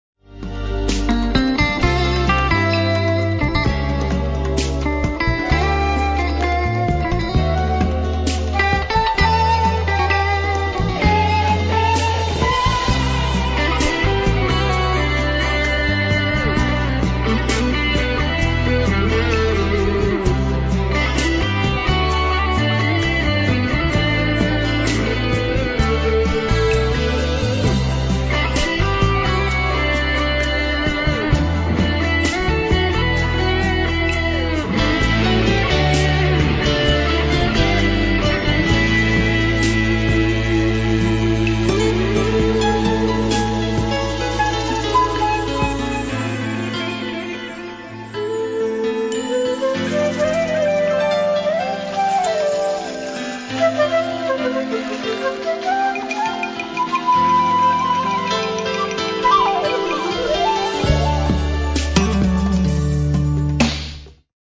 ambijentalno, lagano, a-mol